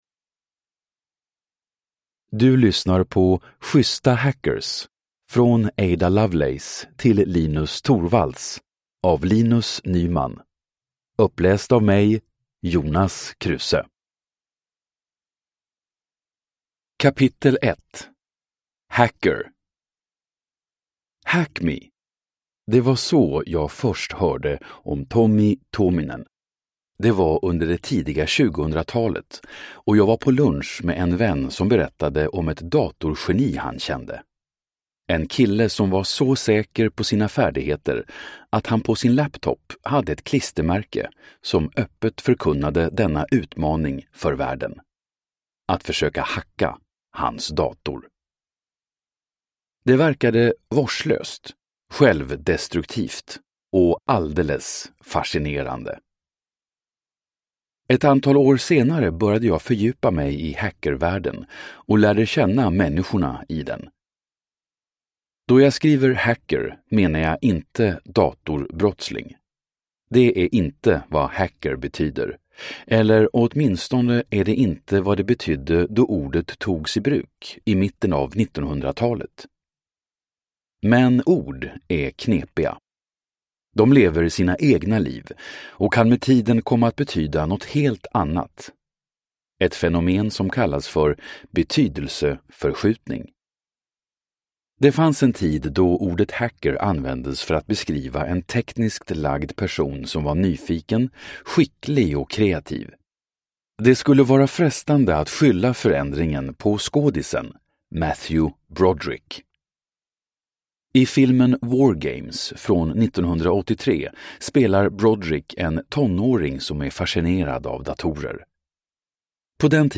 Schyssta hackers (ljudbok) av Linus Nyman